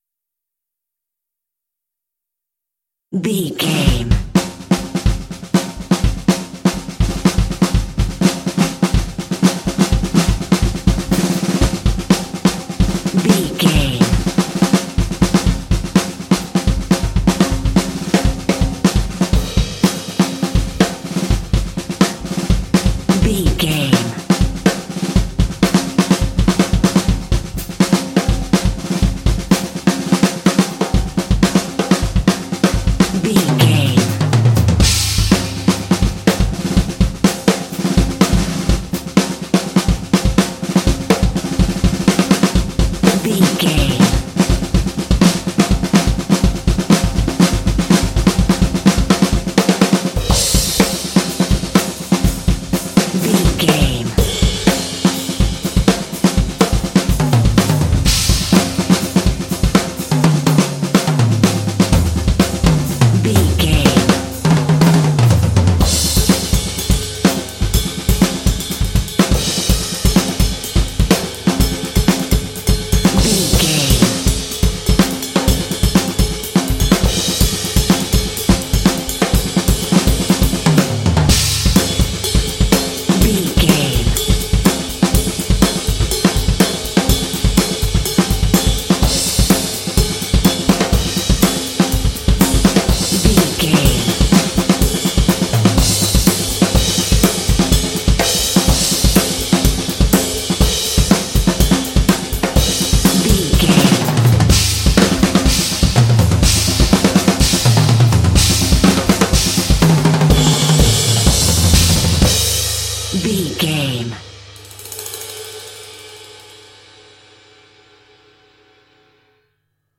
This epic drumline will pump you up for some intense action.
Epic / Action
Atonal
driving
motivational
determined
big
drums
percussion
drumline